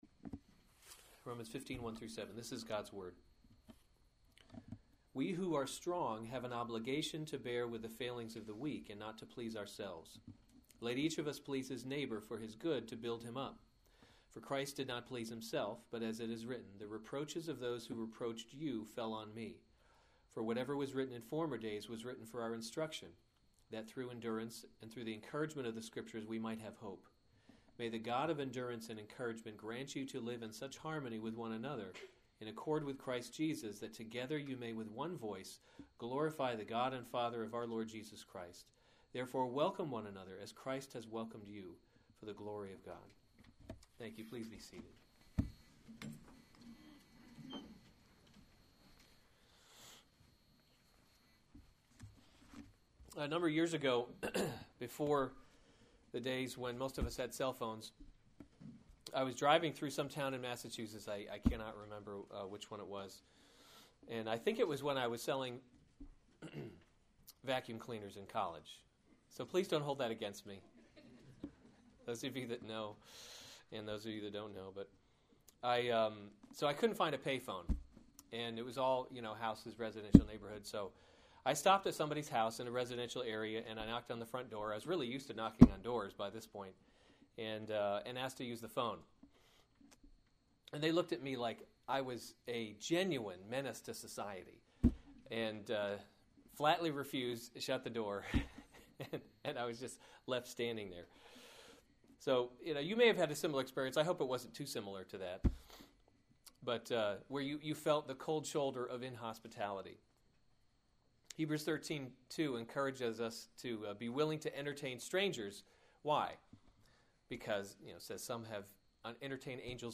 April 25, 2015 Romans – God’s Glory in Salvation series Weekly Sunday Service Save/Download this sermon Romans 15:1-7 Other sermons from Romans The Example of Christ 15:1 We who are strong […]